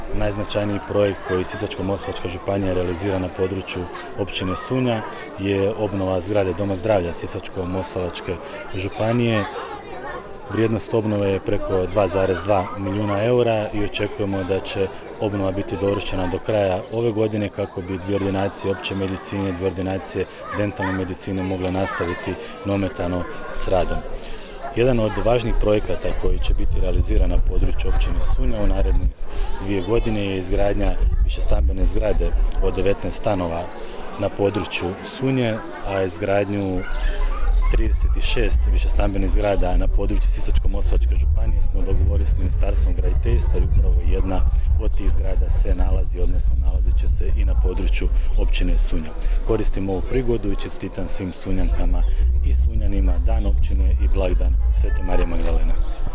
U Sunji je održana svečana sjednica Općinskog vijeća u prigodi obilježavanja Dana Općine Sunja  i blagdana zaštitnice sv. Marije Magdalene, te XXVII. Državna konjogojska izložba.
Celjak je izdvojio i značajnije projekte na području Sunje koji su trenutno u realizaciji